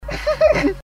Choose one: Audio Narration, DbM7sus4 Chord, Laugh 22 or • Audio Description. Laugh 22